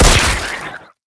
spitter-death-4.ogg